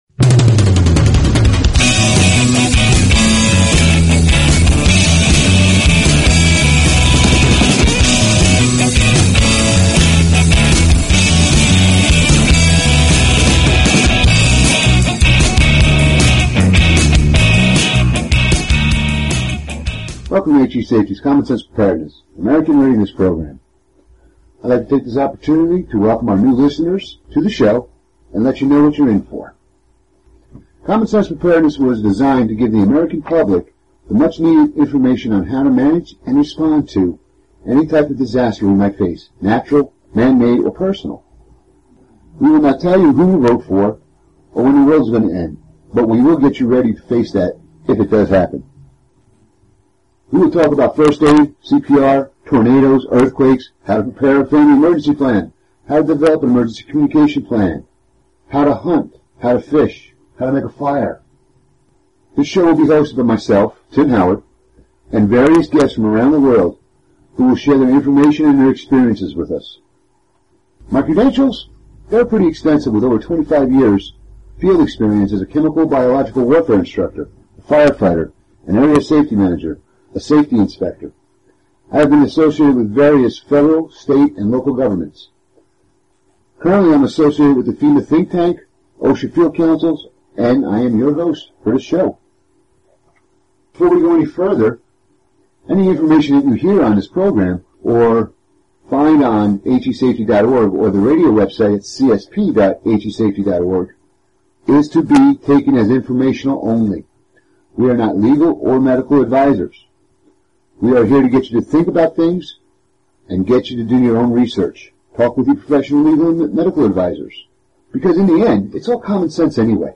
Talk Show Episode, Audio Podcast, Common_Sense_Preparedness and Courtesy of BBS Radio on , show guests , about , categorized as